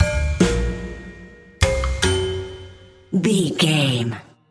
Ionian/Major
B♭
drums
percussion
double bass
silly
circus
goofy
comical
cheerful
perky
Light hearted
quirky